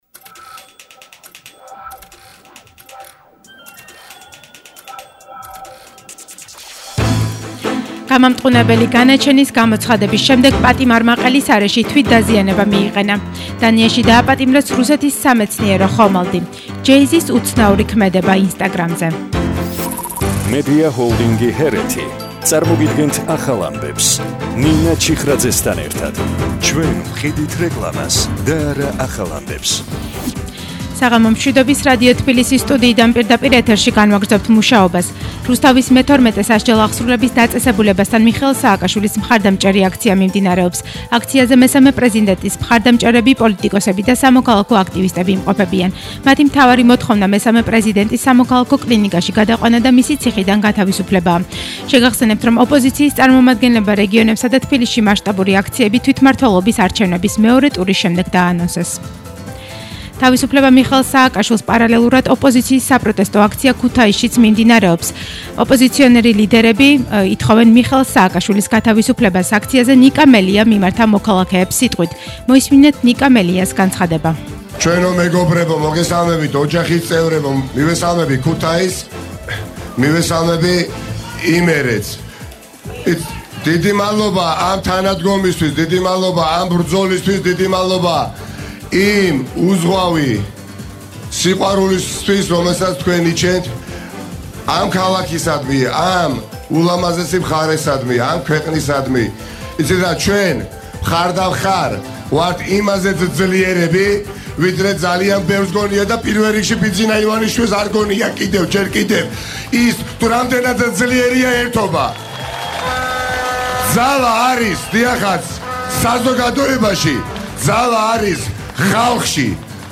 ახალი ამბები 20:00 საათზე –4/11/21